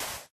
sand2.ogg